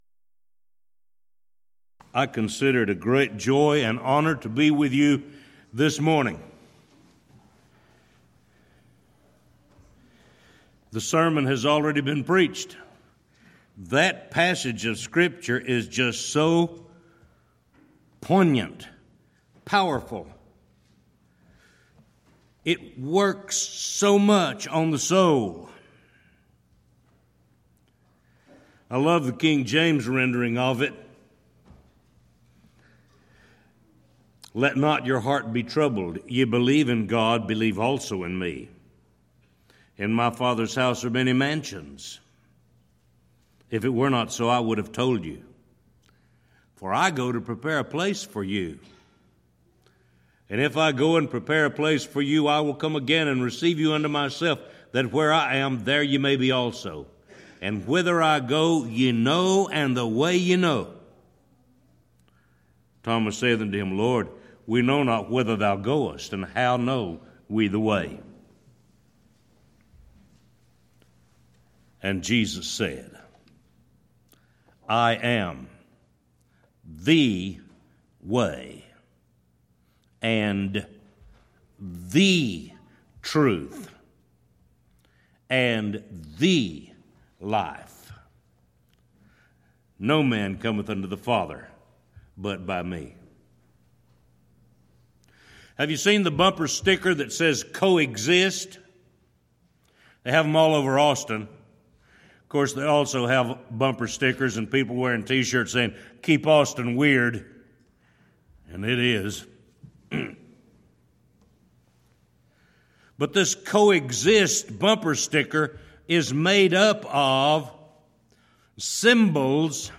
Event: 4th Annual Back to the Bible Lectures Theme/Title: The I Am's of Jesus